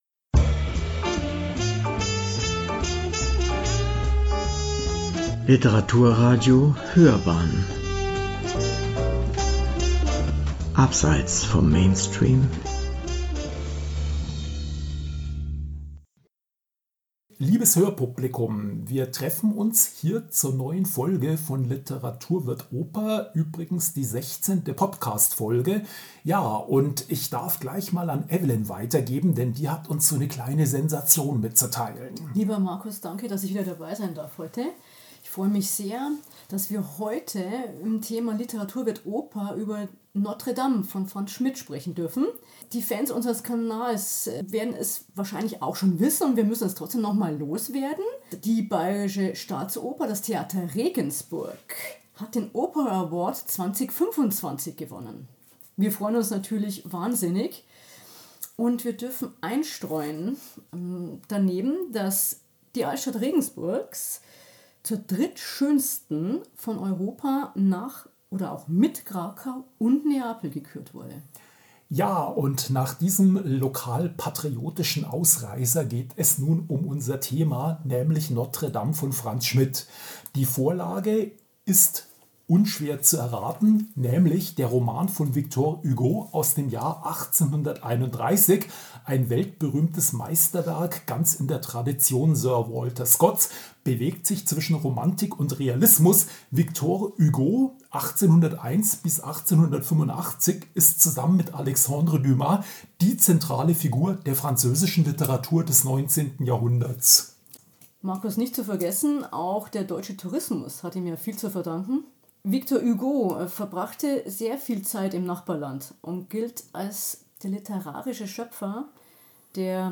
Im Studio des Literaturradio Hörbahn